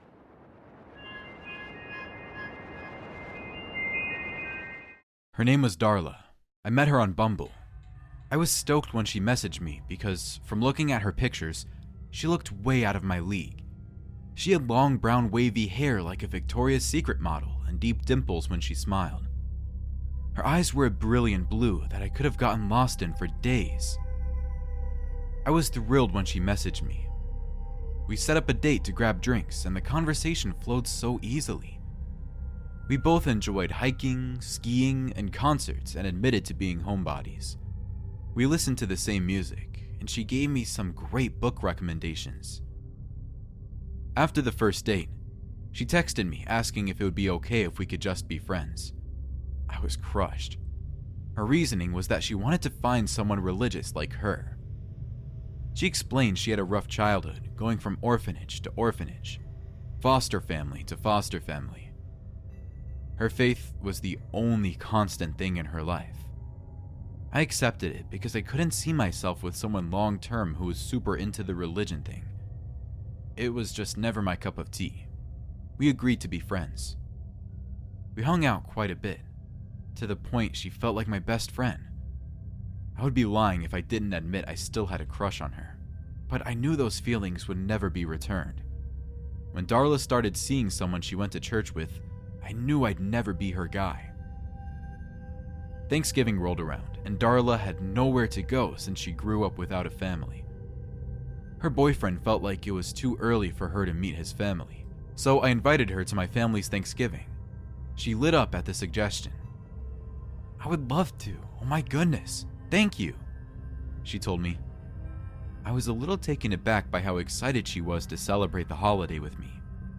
All advertisements are placed exclusively at the beginning of each episode, ensuring complete immersion in our horror stories without interruptions. Experience uninterrupted psychological journeys from start to finish with zero advertising breaks.